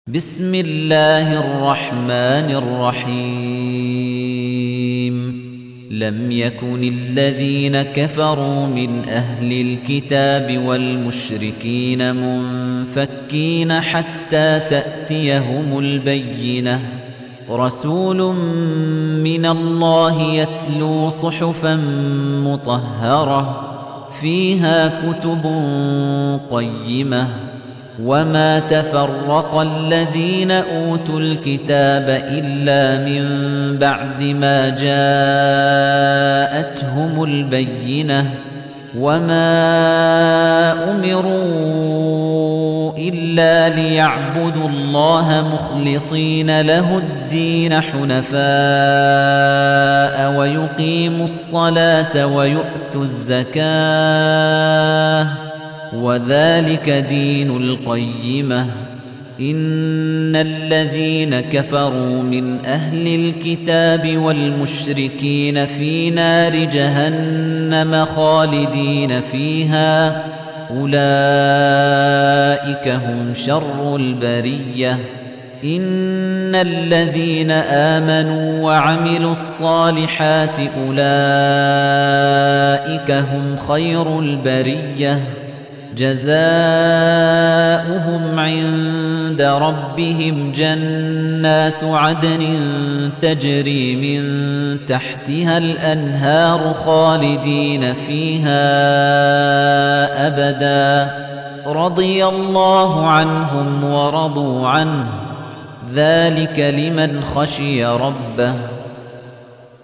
98. Surah Al-Baiyinah سورة البينة Audio Quran Tarteel Recitation
Surah Sequence تتابع السورة Download Surah حمّل السورة Reciting Murattalah Audio for 98.